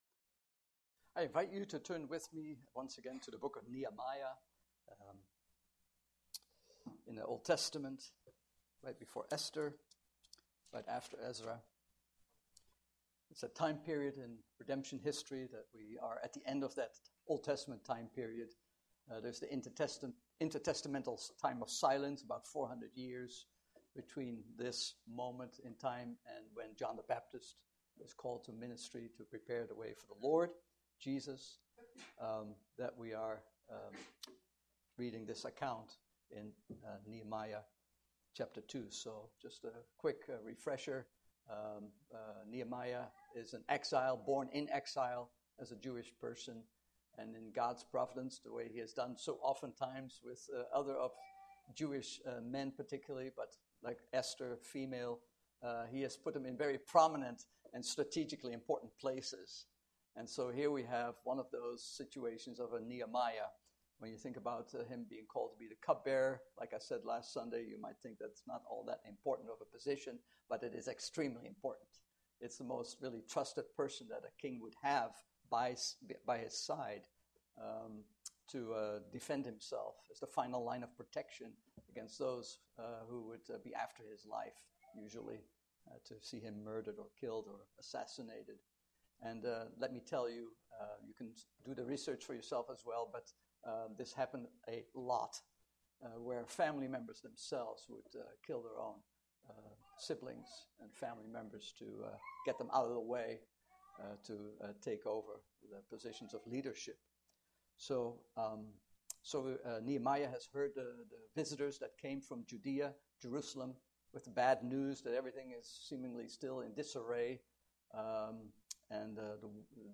Book of Nehemiah Passage: Nehemiah 2:1-9 Service Type: Morning Service « Do You Have Keys to the Church?